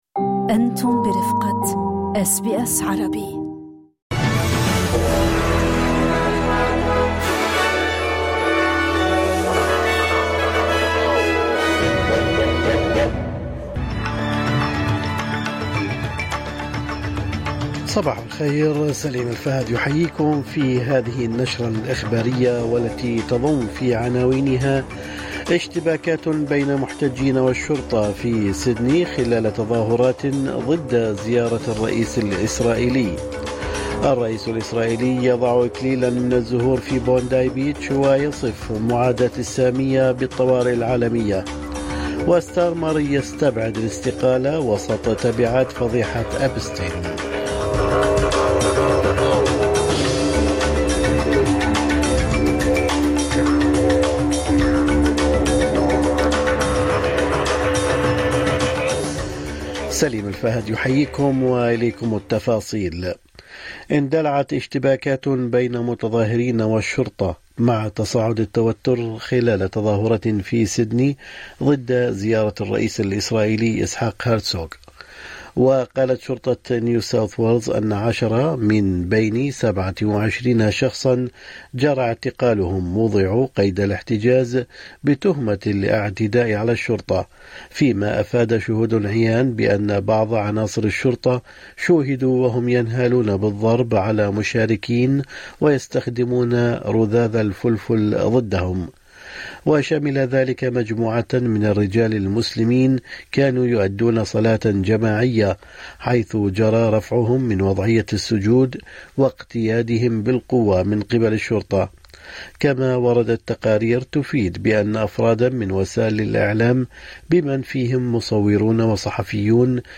يمكنكم الاستماع الى النشرة الاخبارية كاملة بالضغط على التسجيل الصوتي أعلاه.